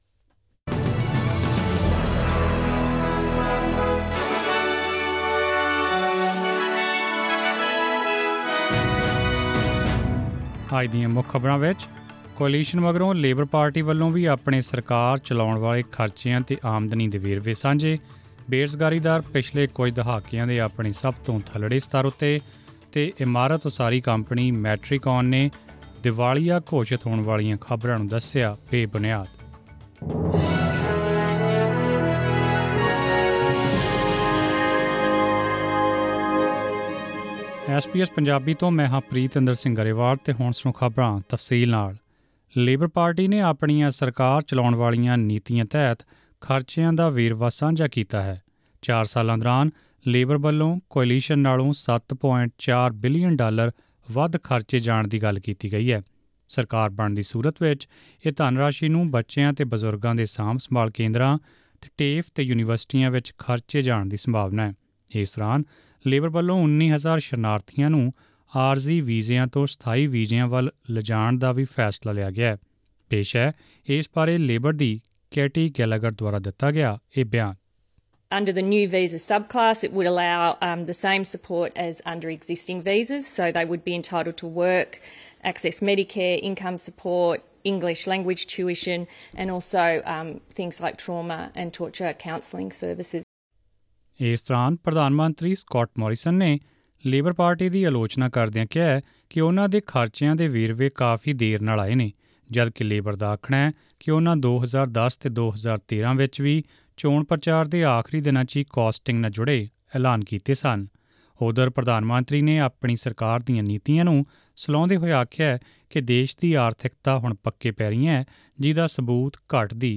Presenting the major national and international news stories of today; sports, currency exchange rates and the weather forecast for tomorrow. Click on the audio button to listen to the news bulletin in Punjabi.